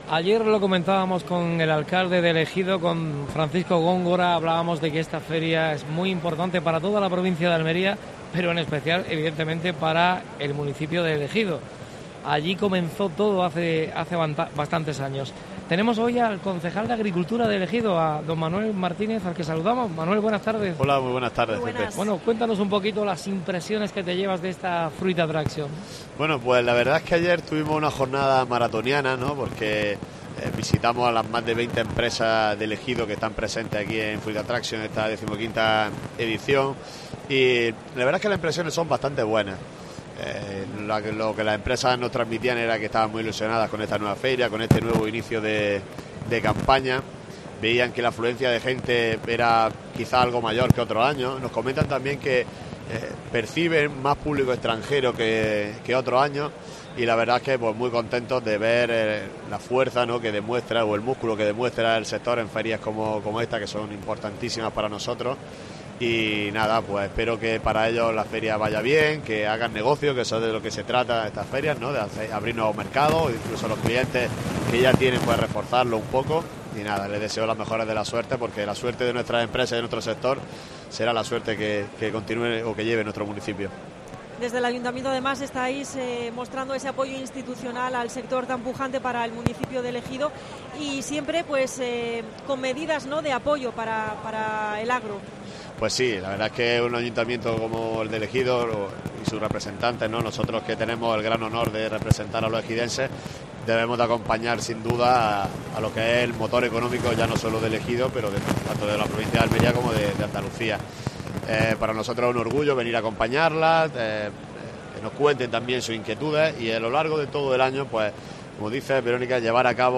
Especial Fruit Attraction (Madrid). Entrevista al concejal de Agricultura del Ayuntamiento de El Ejido.